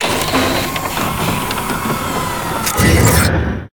CosmicRageSounds / ogg / general / combat / enemy / droid / repair.ogg
repair.ogg